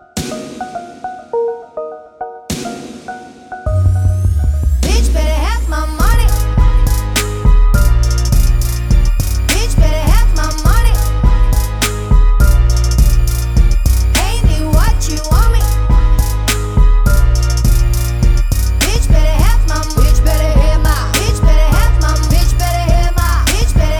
no end Backing Vocals R'n'B / Hip Hop 3:38 Buy £1.50